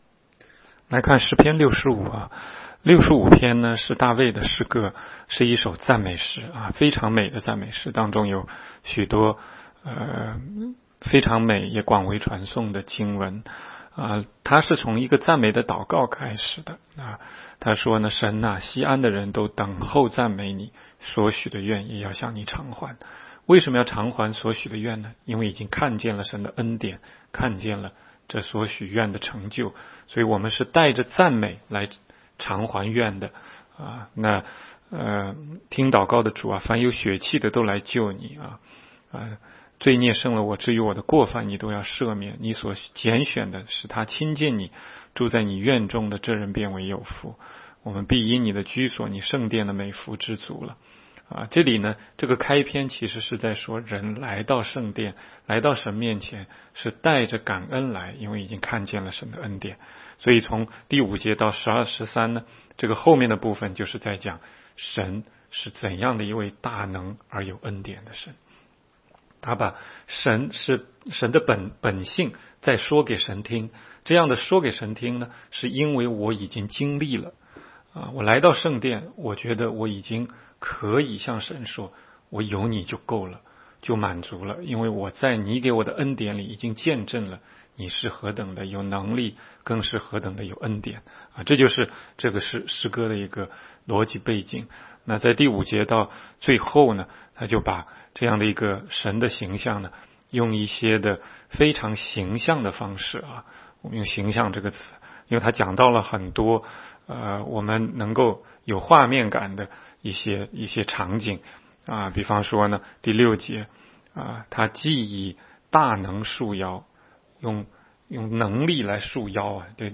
16街讲道录音 - 每日读经-《诗篇》65章